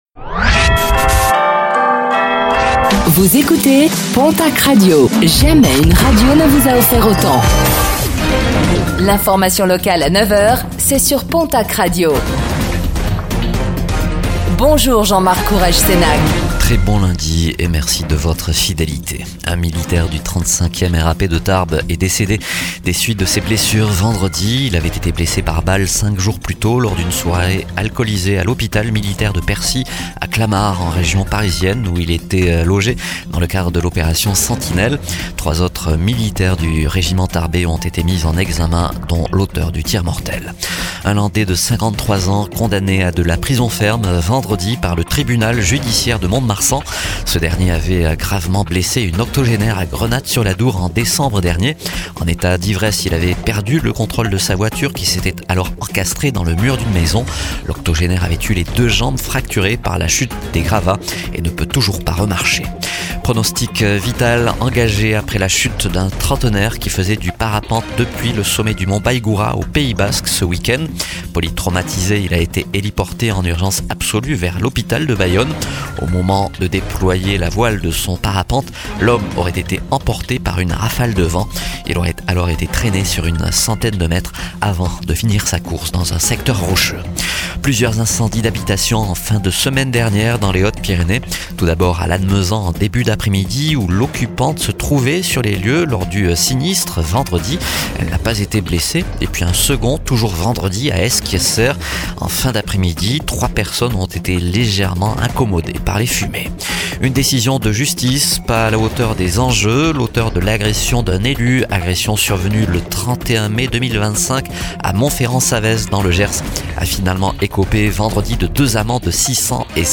Infos | Lundi 23 février 2026